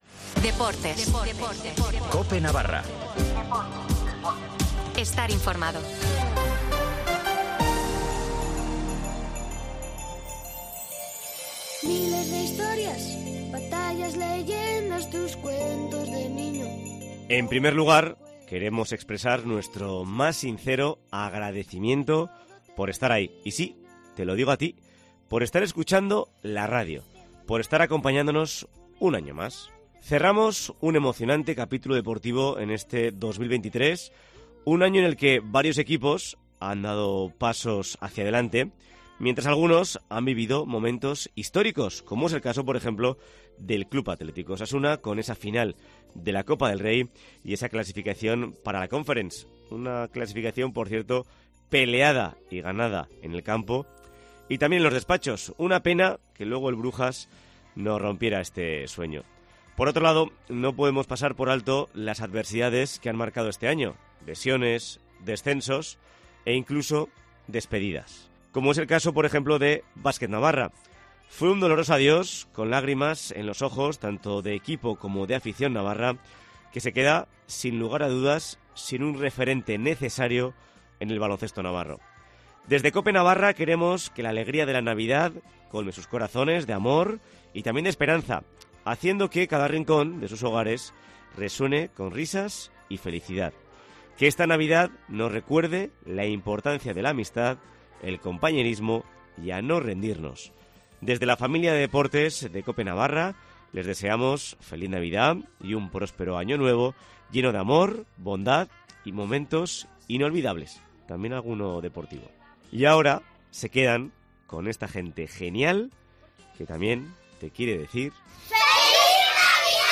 Una veintena de felicitaciones y deseos unidos con un villancico de fondo